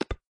click-sound.mp3